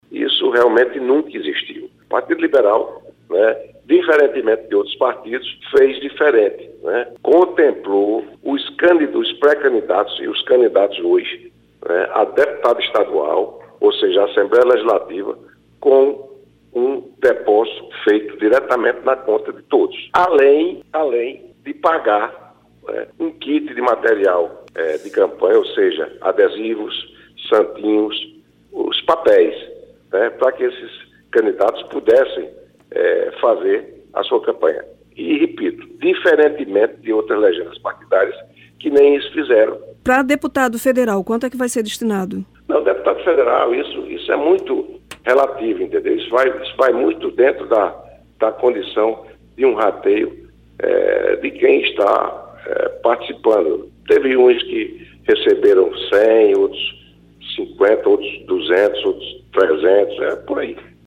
O comentário do parlamentar foi registrado pelo programa Correio Debate, da 98 FM, de João Pessoa, nesta quinta-feira (08/09).